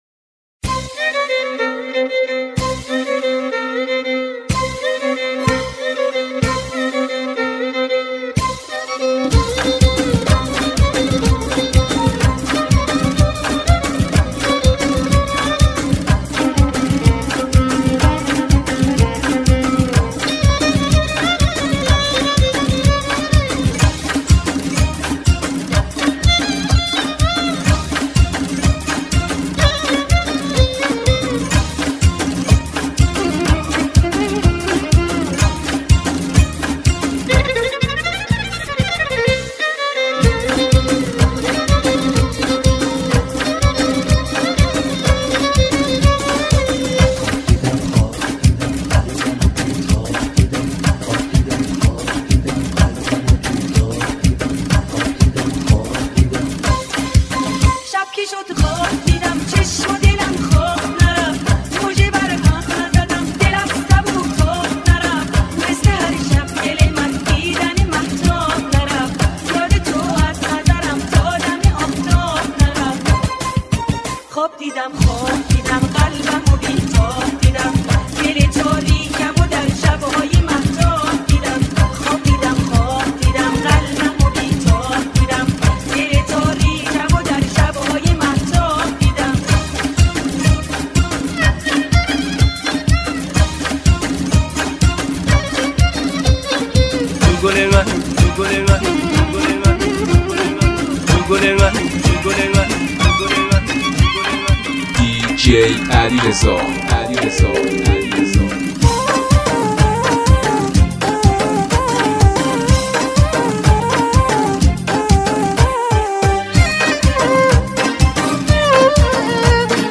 ژانر: پاپ
ریمیکسی جدید از شادترین های دهه 60 و 70